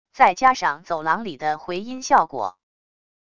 再加上走廊里的回音效果wav音频